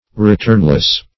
Returnless \Re*turn"less\, a. Admitting no return.